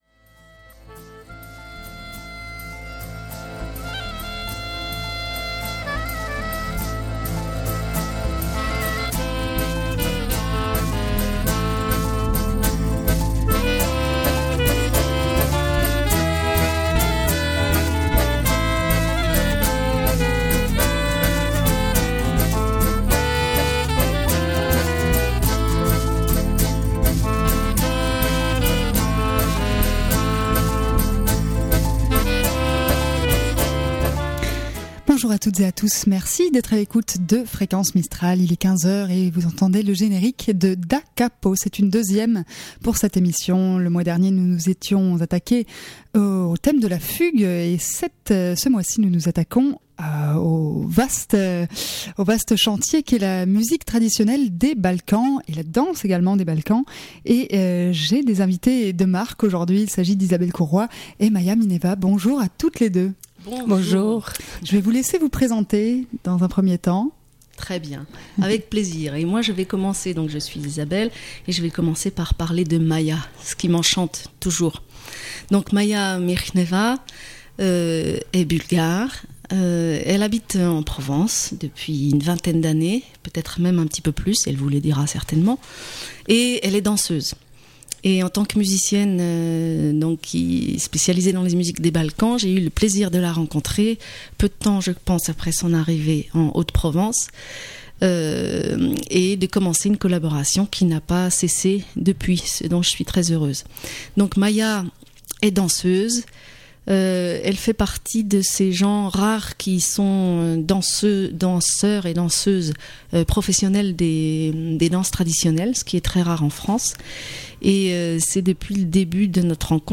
« Da Capo » autrement dit, « depuis le début », se propose d’être une émission durant laquelle seront abordées les musiques dites classiques, ainsi que les musiques traditionnelles.